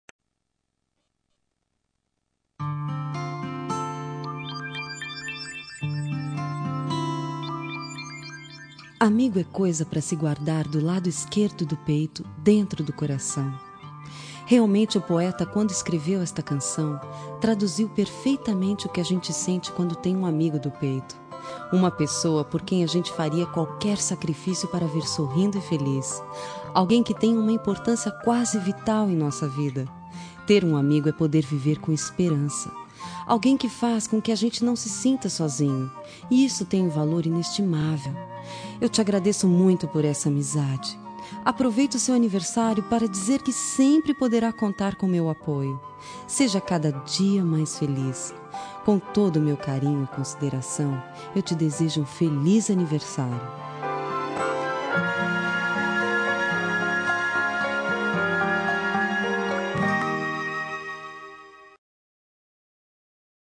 Telemensagem de Aniversário de Amigo – Voz Feminina – Cód: 1555